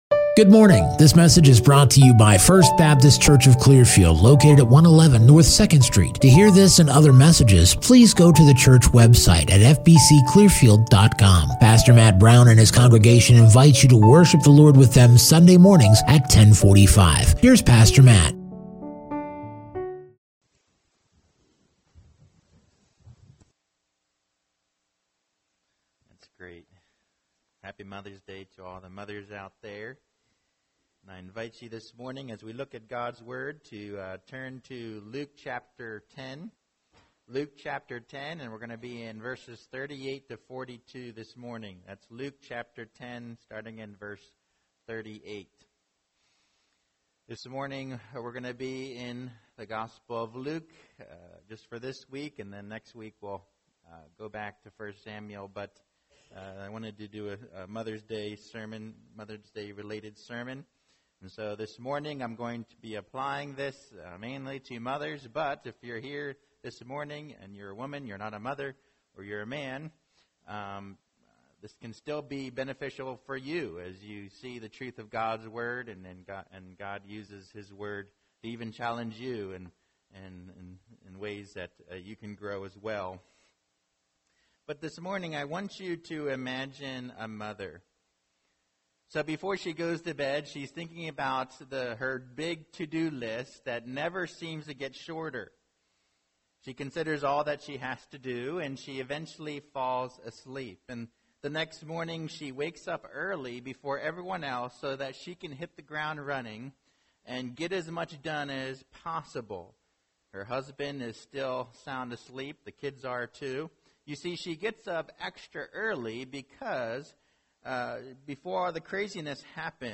Sermon Passage: Luke 10:38-42